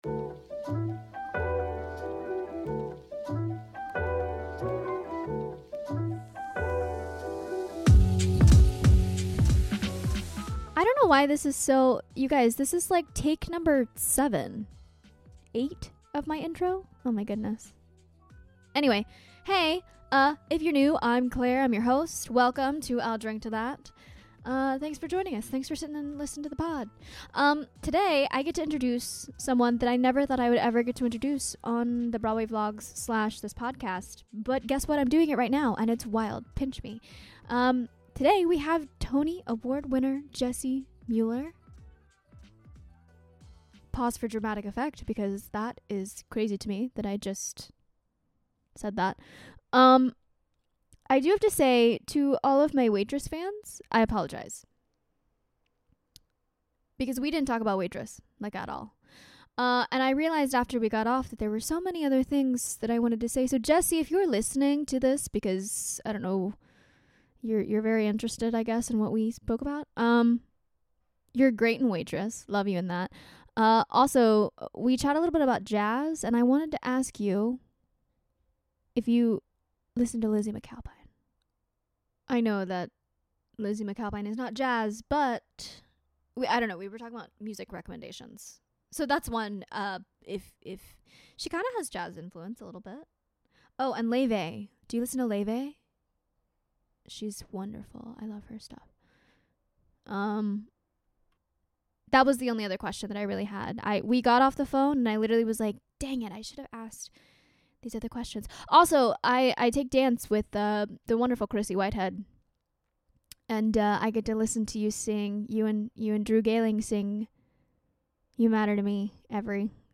Tony Award Winner Jessie Mueller is on the podcast! She joins me over zoom as we chat about what she's binge-watching, her career on Broadway, and of course, Carole King.
🎤 Shure SM58 with Focusrite